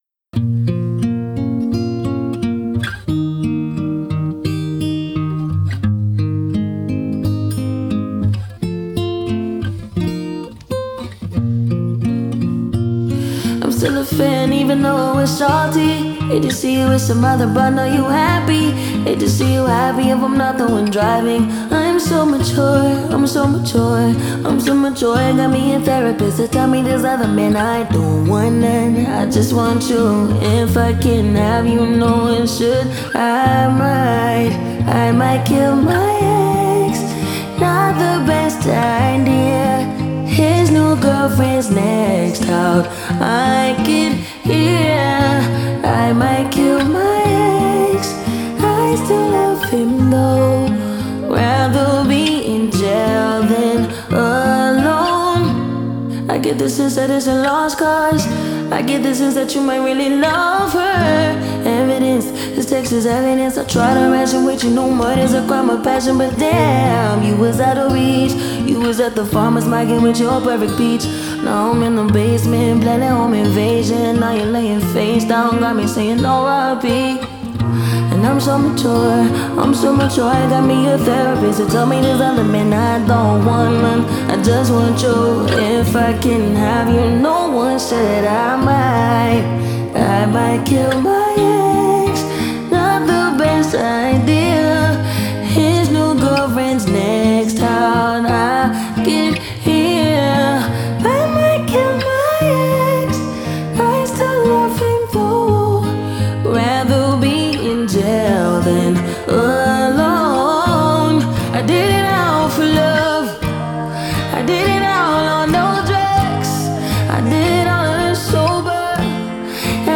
singer and song composer